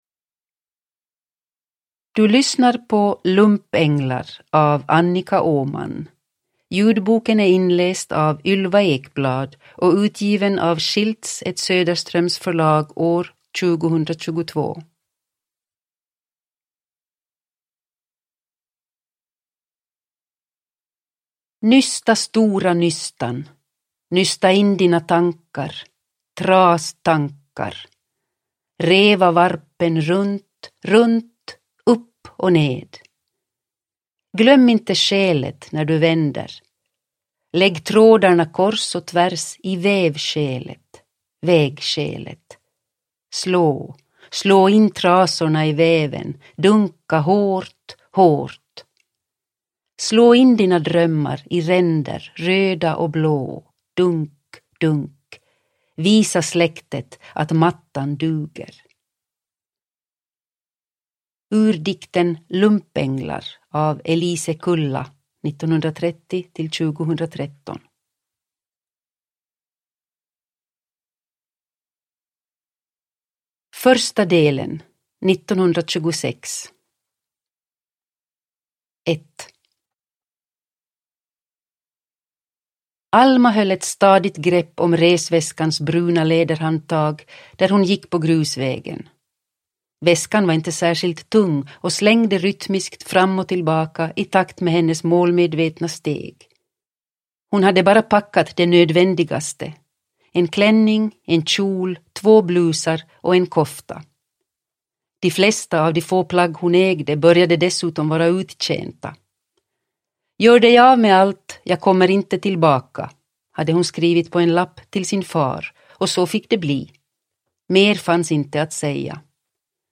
Lumpänglar – Ljudbok – Laddas ner